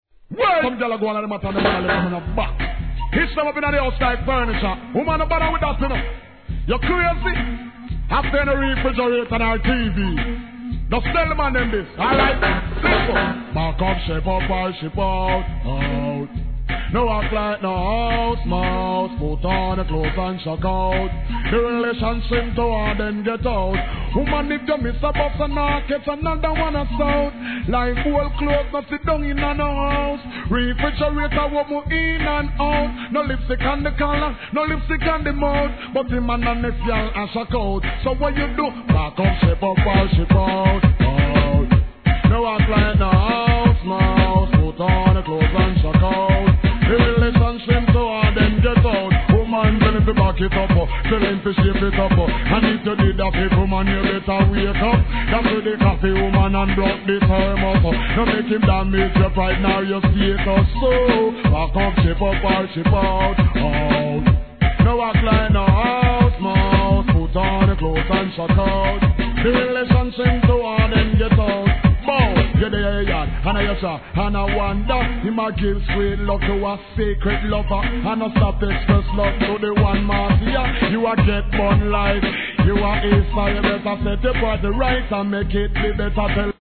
12inch
1. REGGAE